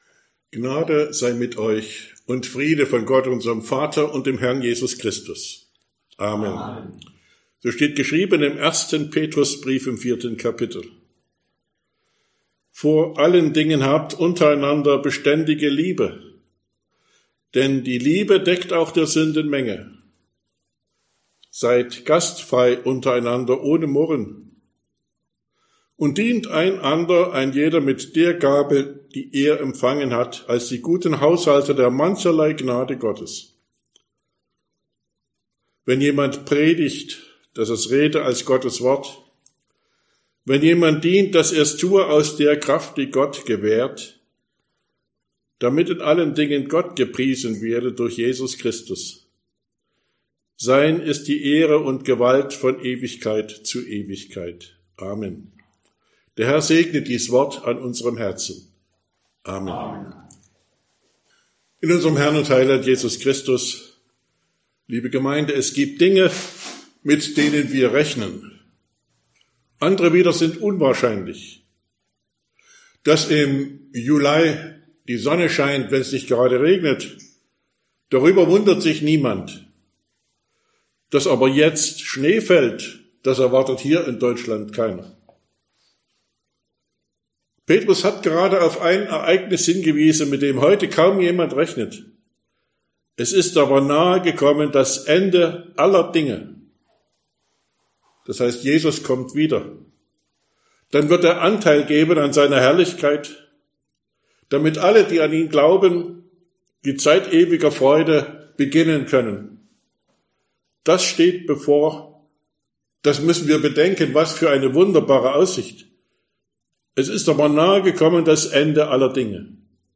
Sonntag nach Trinitatis Passage: 1. Petrus 4, 9-11 Verkündigungsart: Predigt « 4.